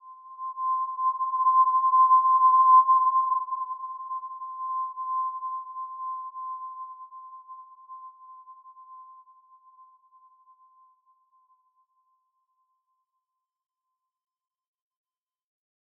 Simple-Glow-C6-mf.wav